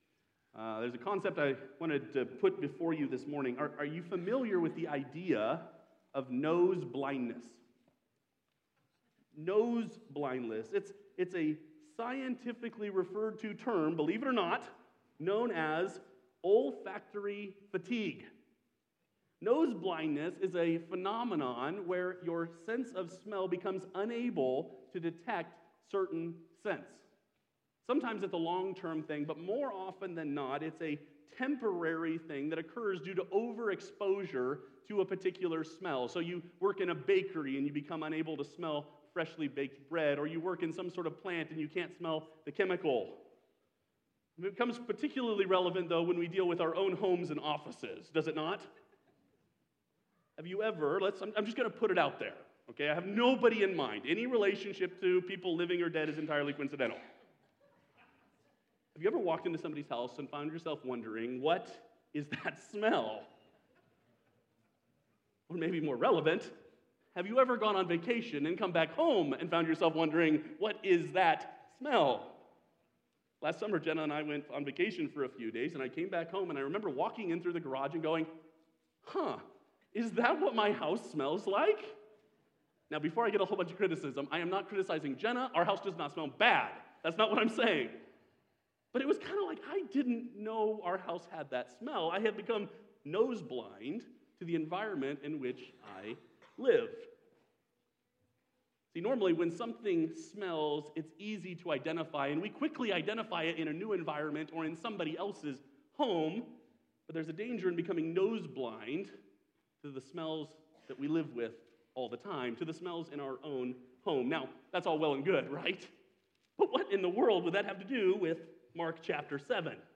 Sermons from Faith Bible Church in Lincoln, NE